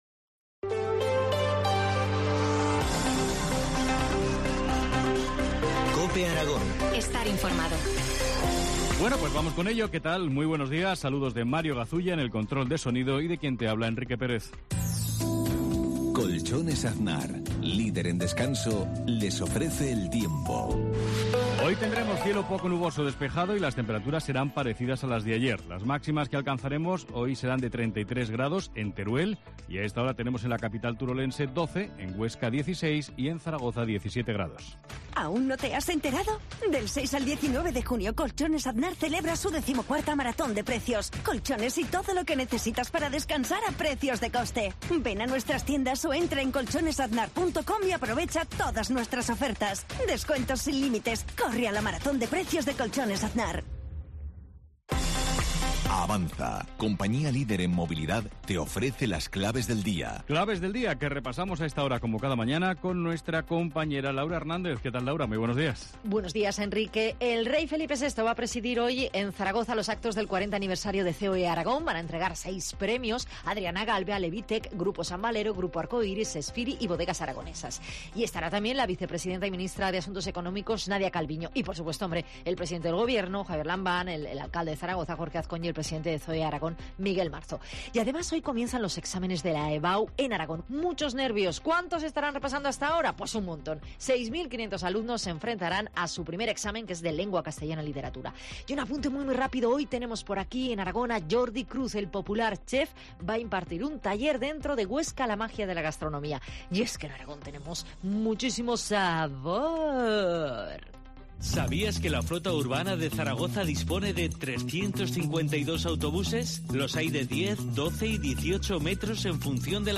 Informativo local